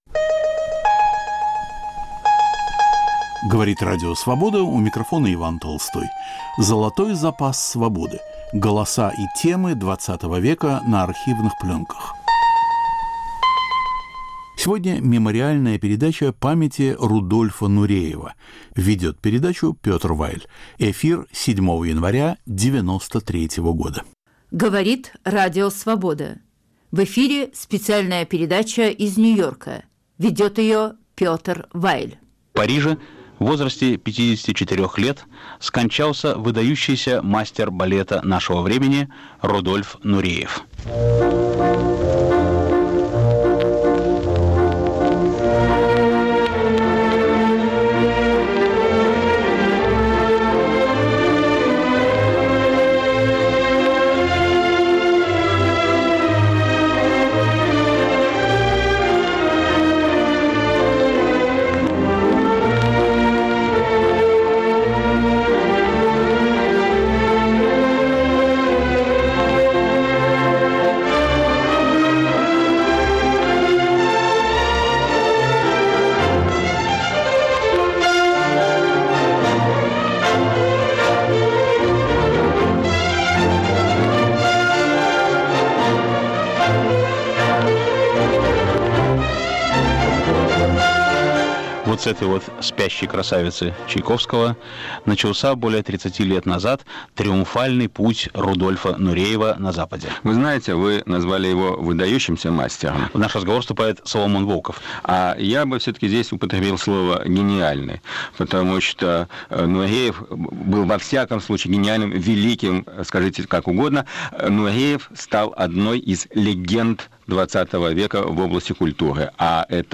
Вспоминая легендарного танцовщика XX века. Передача из Нью-Йорка. Ведущий Петр Вайль, участвует Соломон Волков.
Беседа с Владимиром Маканиным.